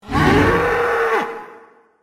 minotauro-sonido.mp3